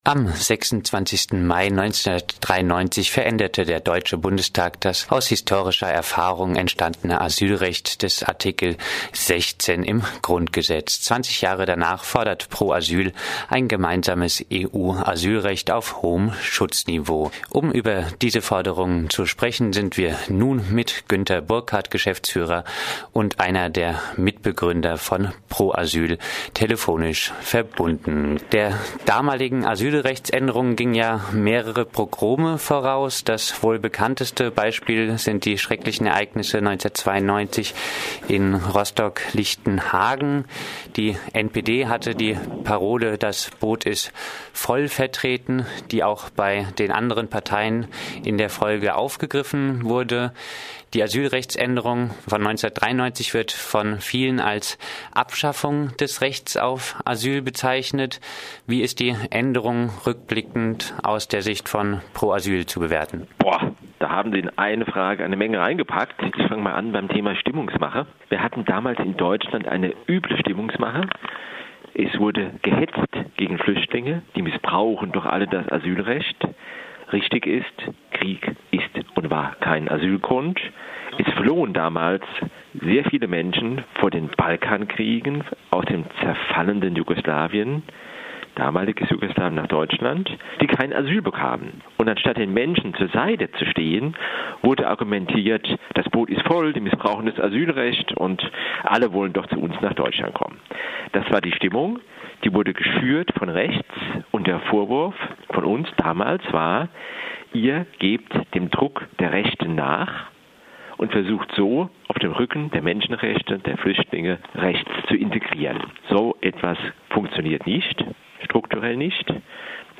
Aufenthaltsrecht von Abwehrrecht zu Integrationsrecht umbauen. Pro Asyl, 20 Jahre nach de facto Abschaffung des Grundrechts auf Asyl. (Gespräch